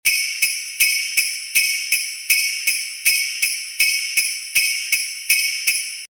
Download Sleigh Bells sound effect for free.
Sleigh Bells